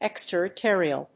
音節ex・ter・ri・to・ri・al 発音記号・読み方
/èkstèrətˈɔːriəl(米国英語)/